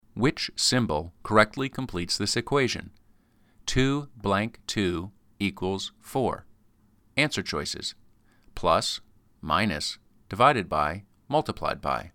All of the descriptions were recorded.
These were identified by the word "blank."